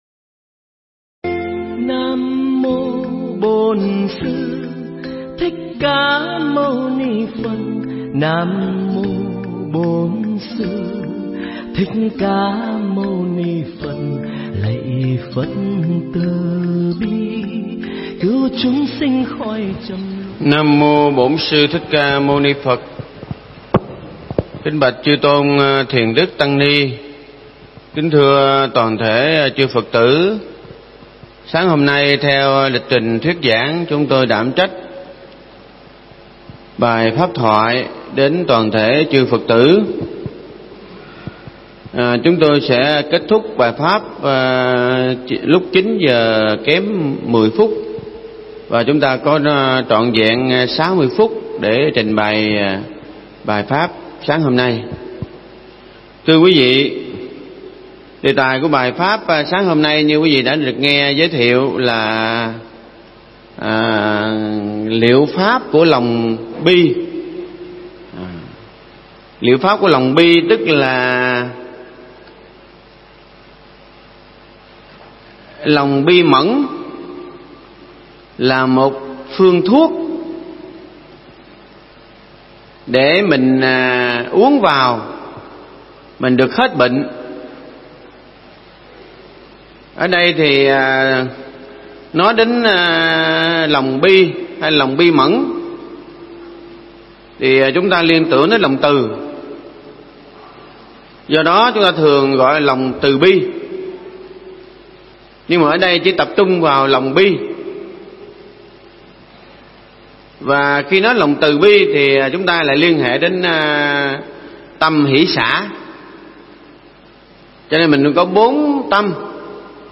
Nghe Mp3 thuyết pháp Liệu Pháp Của Lòng Bi